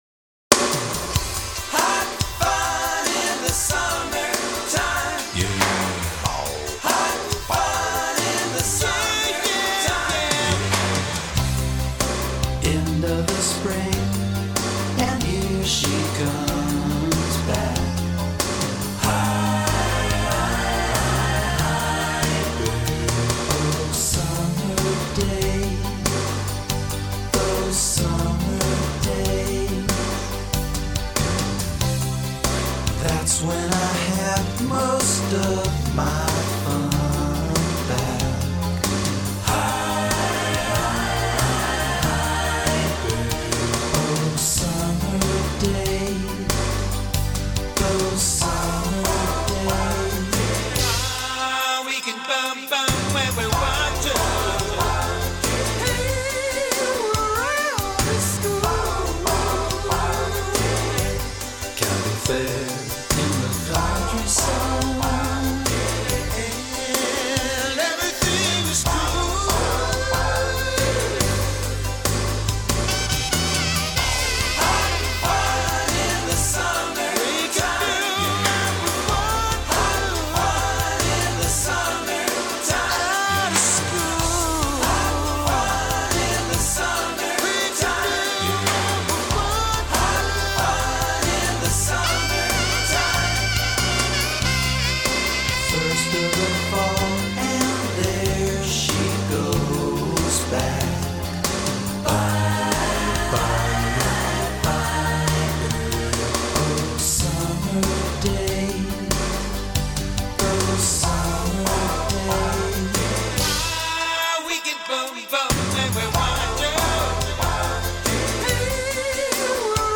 Рок Поп рок